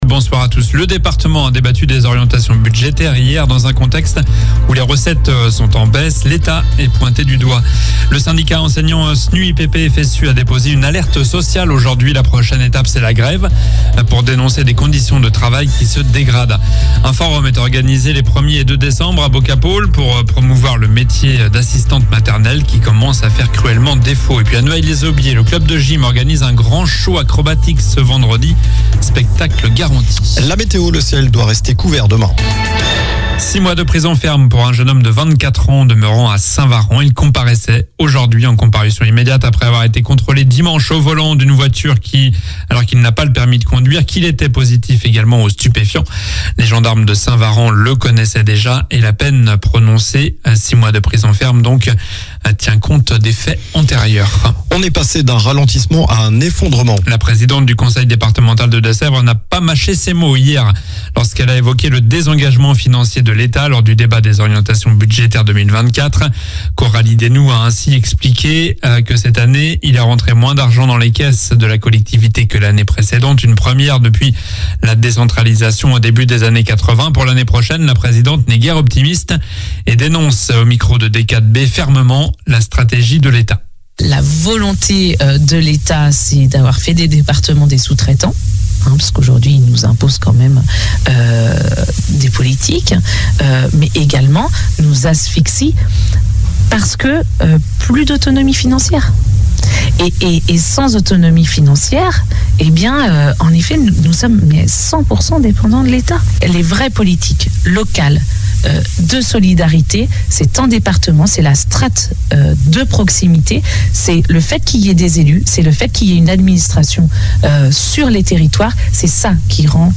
Journal du mardi 28 novembre (soir)